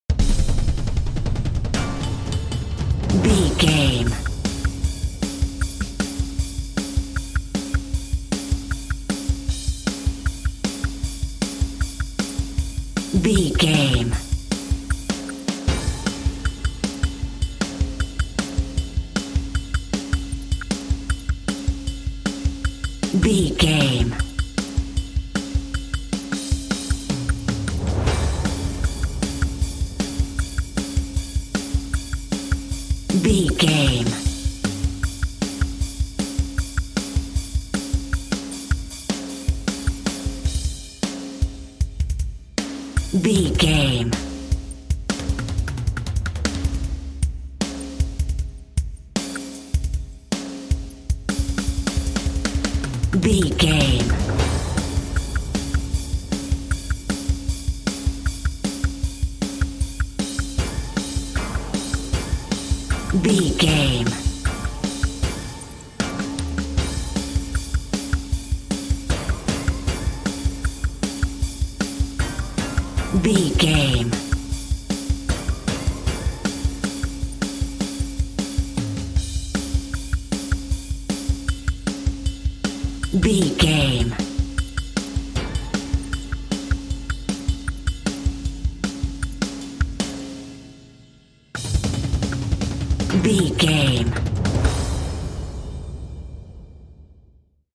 Atonal
Fast
frantic
synth
8bit
aggressive
driving
energetic
intense